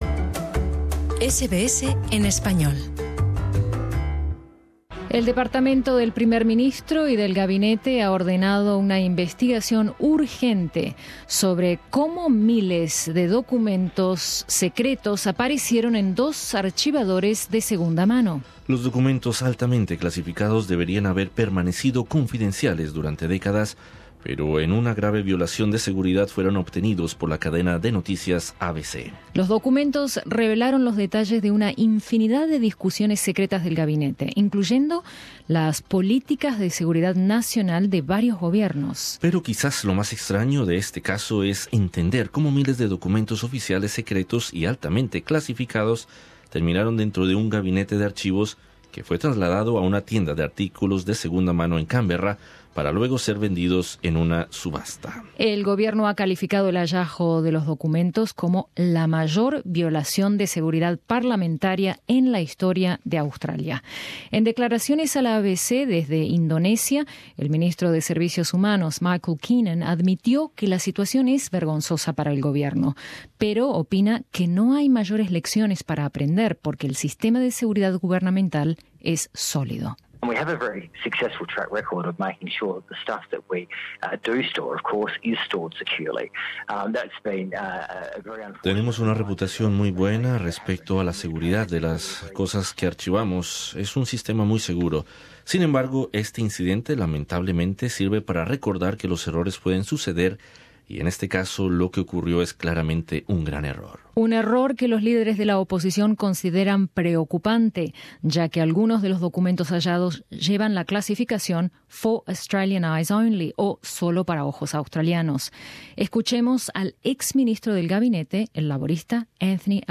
Entrevista con el alcalde de Alella en Cataluña, Andreu Francisco.